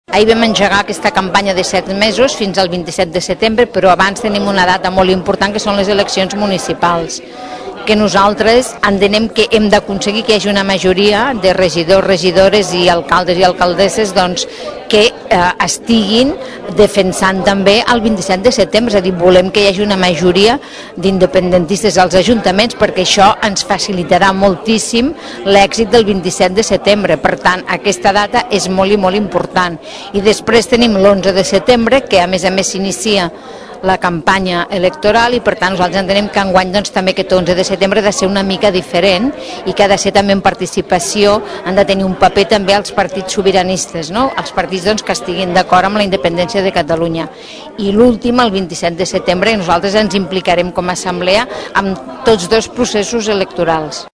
Per això demanen a les candidatures municipals que prioritzin els pactes independentistes, per tenir el màxim d’ajuntaments a favor del procés. Així ho explicava Carme Forcadell, en declaracions a Ràdio Tordera.